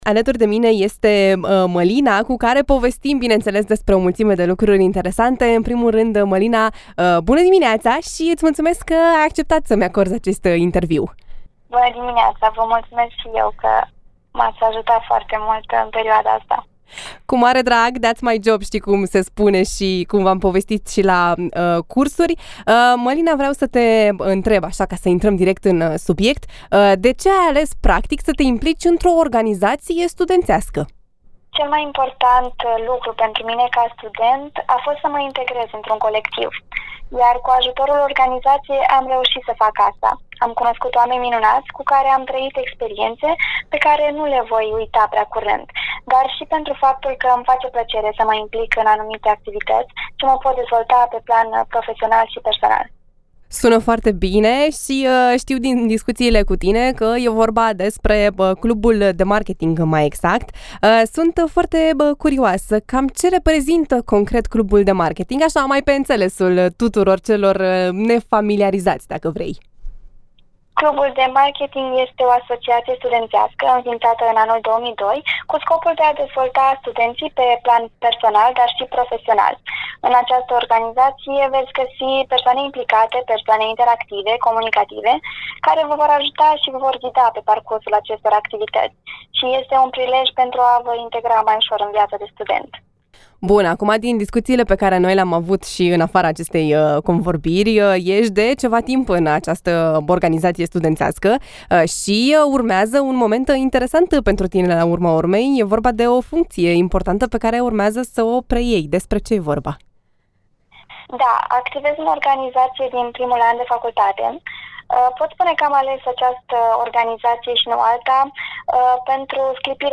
Studenţii suceveni sunt în continuare preocupaţi şi de dezvoltarea personală – INTERVIU